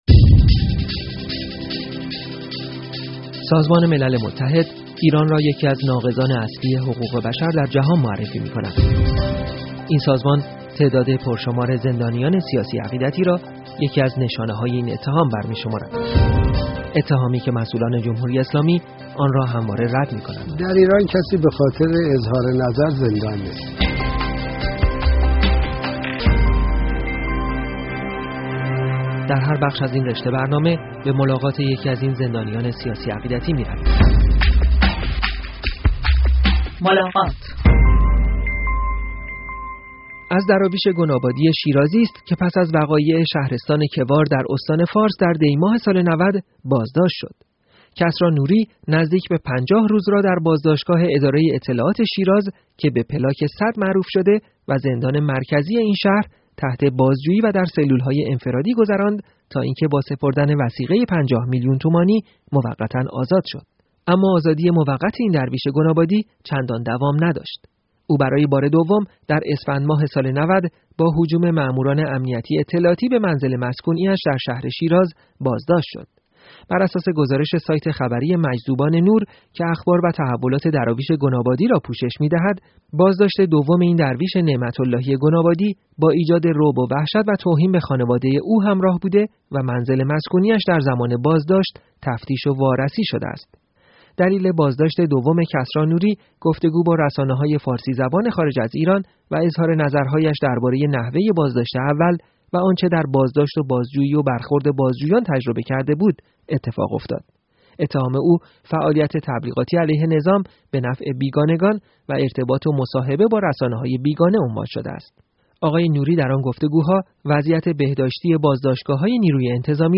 برنامه ملاقات از شنبه تا پنج شنبه راس ساعت های 11صبح، 16 و 22 به وقت تهران از رادیو فردا پخش می شود.